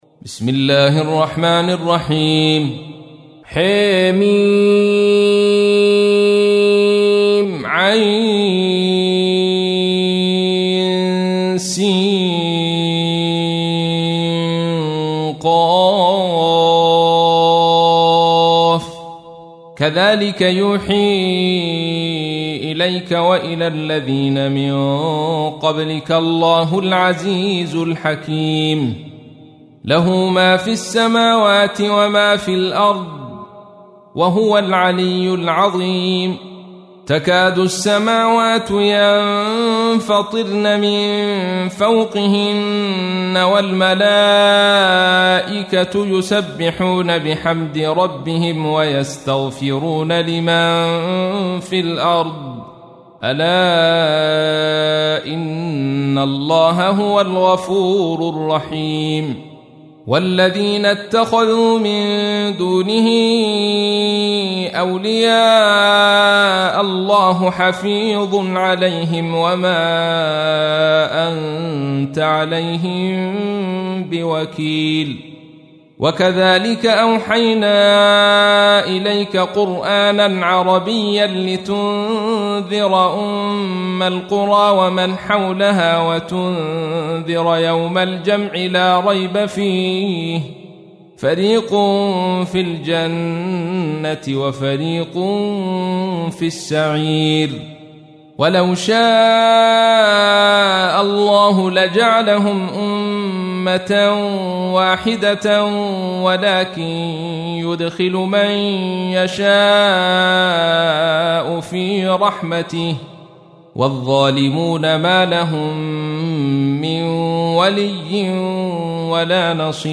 تحميل : 42. سورة الشورى / القارئ عبد الرشيد صوفي / القرآن الكريم / موقع يا حسين